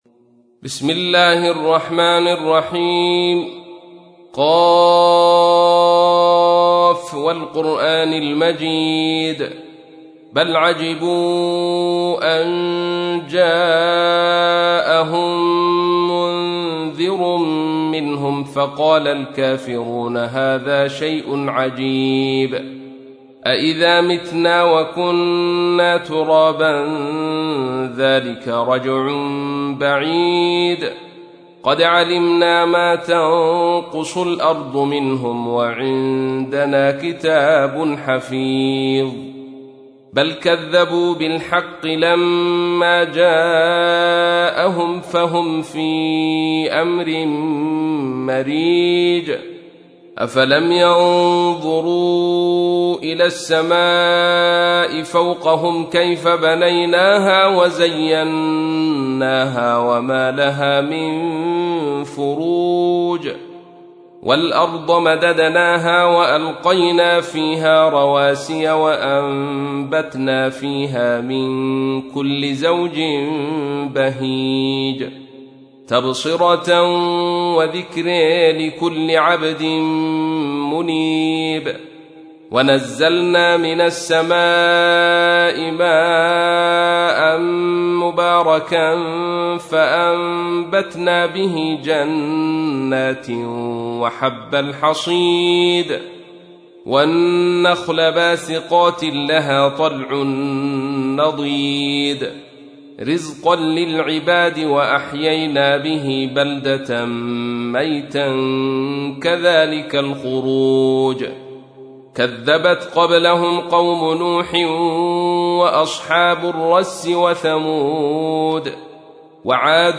تحميل : 50. سورة ق / القارئ عبد الرشيد صوفي / القرآن الكريم / موقع يا حسين